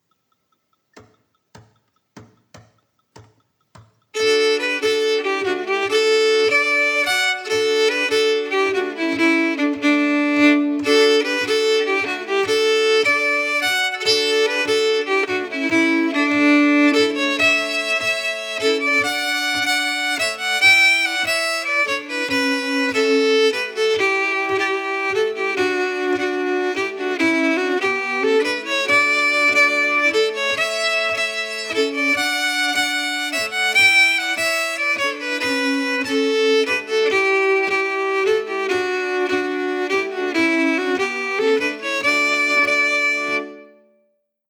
Key: D
Form: Pols (Norwegian polska)
Melody emphasis
M:8/8
Genre/Style: Norwegian pols (polska)